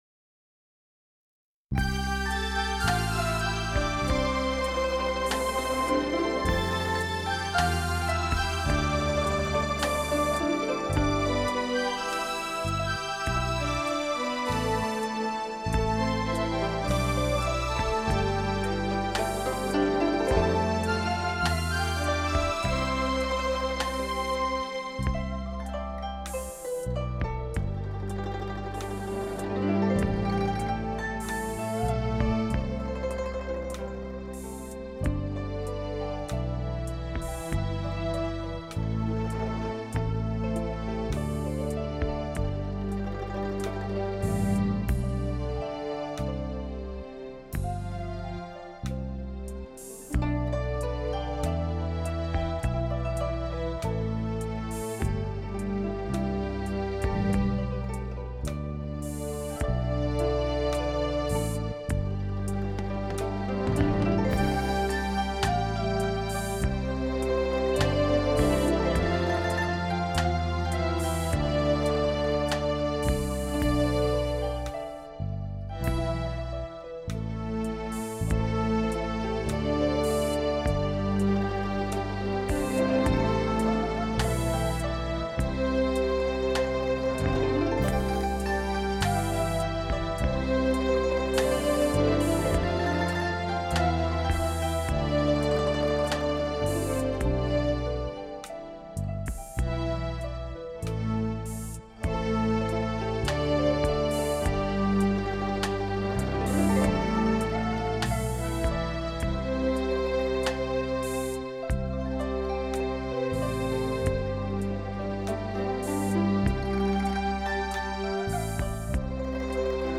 殿堂级声效典范，6.1DTS-ES示范碟，美伦美奂的立体音场，震撼梦幻的音乐享受，无法想象的听觉体验。